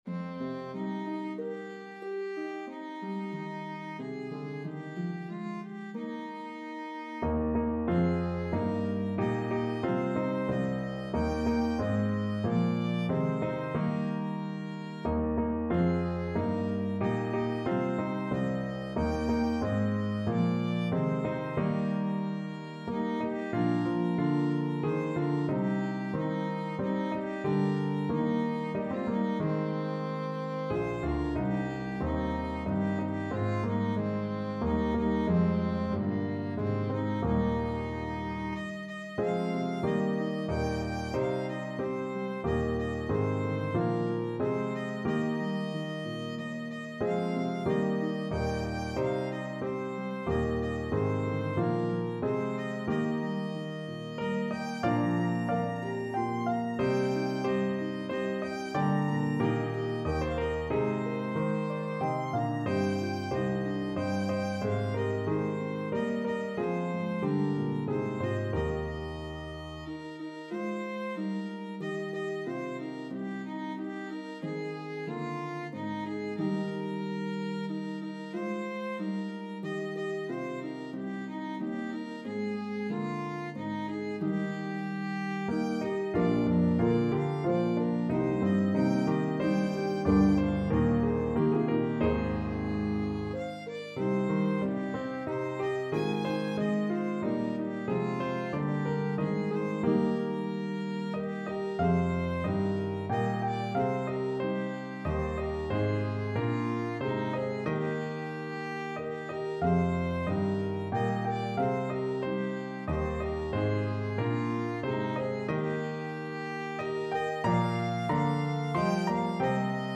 A meditative trio arrangement
is a pentatonic hymn tune